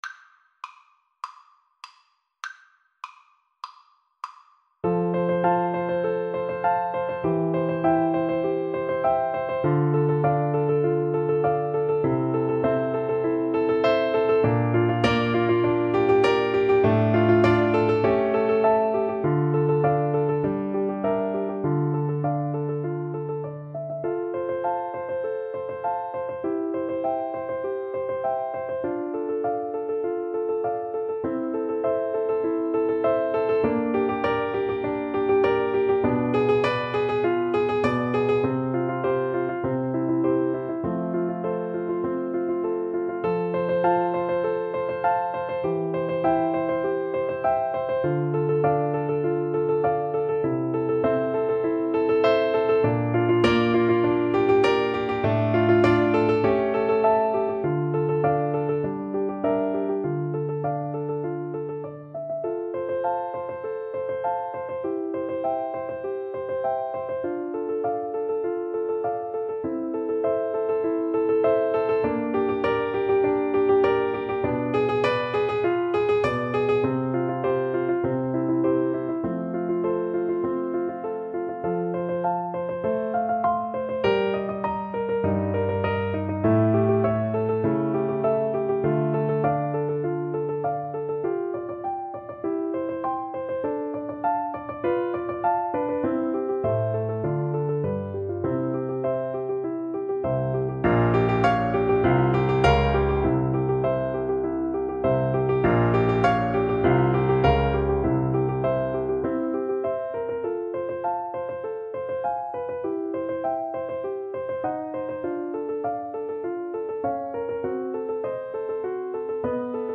Play (or use space bar on your keyboard) Pause Music Playalong - Piano Accompaniment Playalong Band Accompaniment not yet available reset tempo print settings full screen
F major (Sounding Pitch) (View more F major Music for Flute )
4/4 (View more 4/4 Music)
Allegro moderato (View more music marked Allegro)